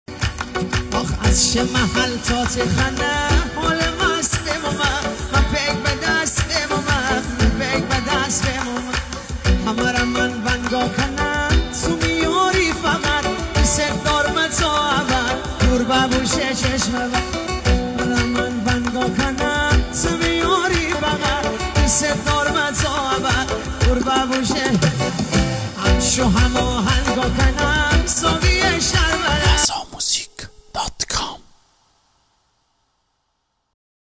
ریمیکس با صدای بچه